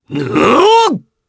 Roman-Vox_Attack4_jp.wav